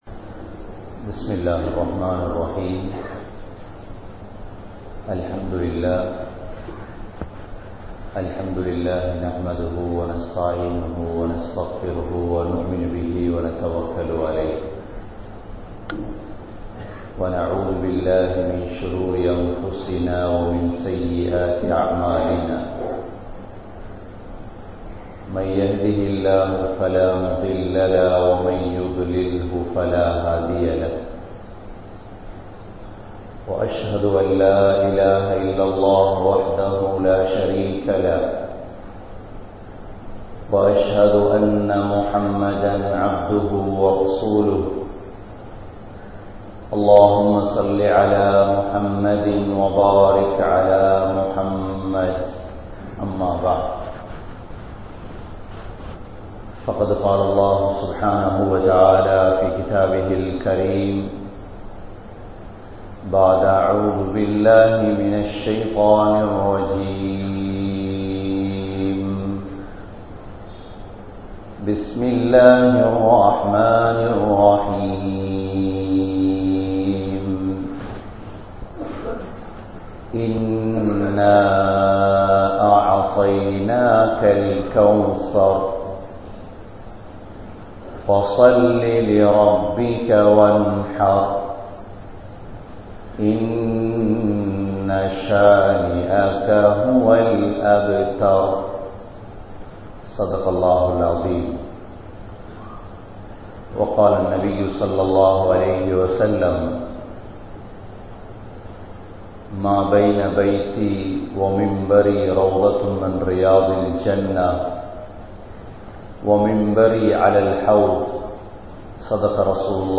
Muminum Paavium Samanaa? (முஃமினும் பாவியும் சமனா??) | Audio Bayans | All Ceylon Muslim Youth Community | Addalaichenai
Oluvil 05, Ansari Jumua Masjith